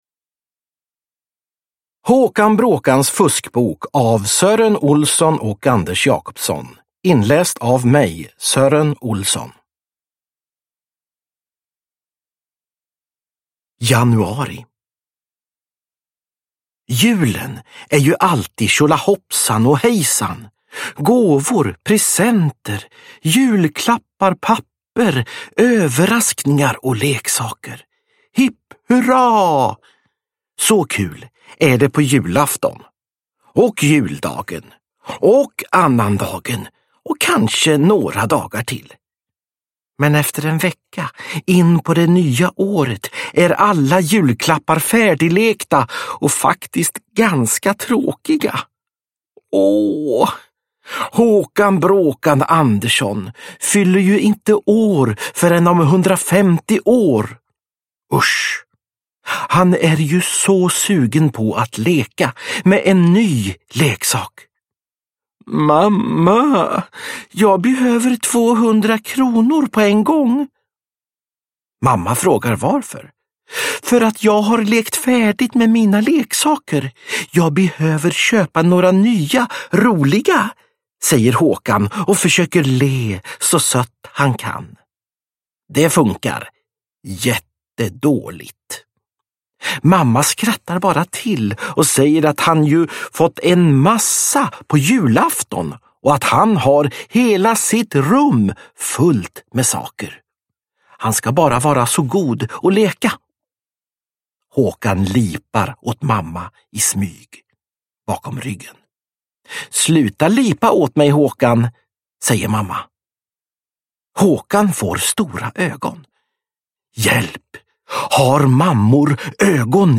Håkan Bråkans fuskbok – Ljudbok – Laddas ner
Uppläsare: Sören Olsson